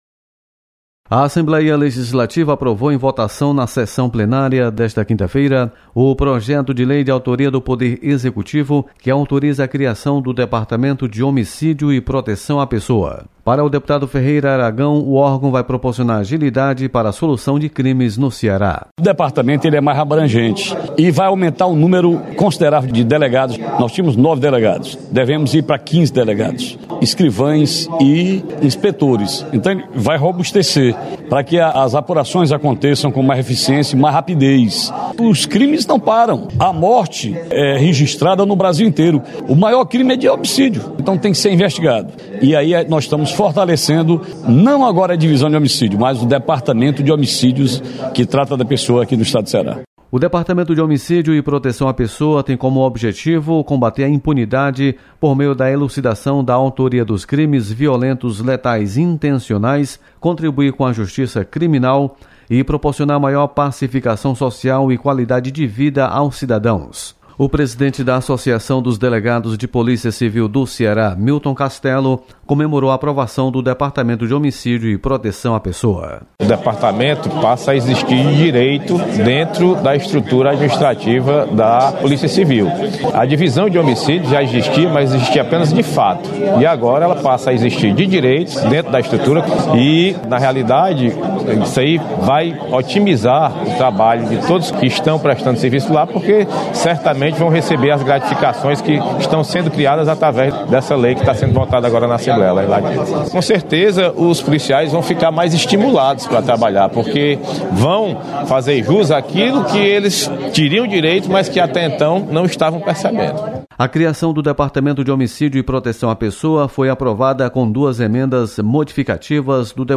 Assembleia Legislativa aprova criação do Departamento de Homicídio e Proteção à Pessoa. Repórter